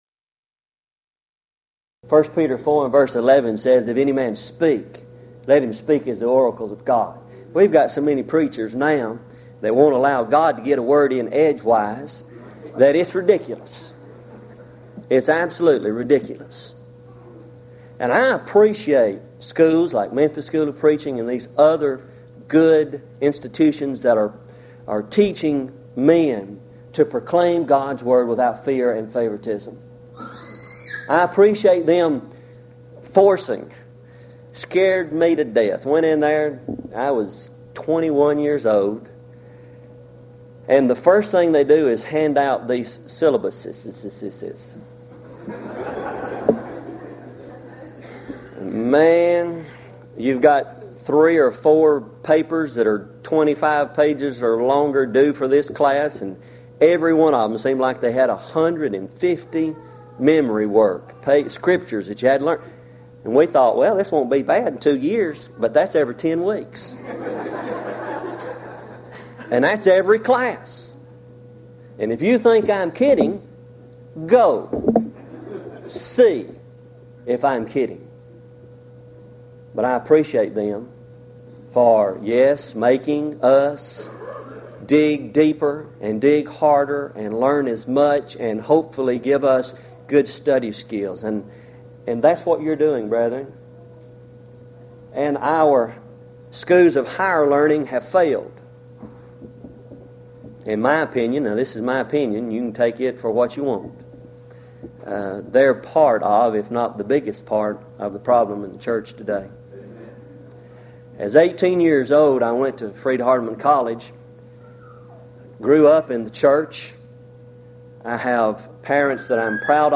Event: 1995 HCB Lectures Theme/Title: The Book Of Isaiah - Part I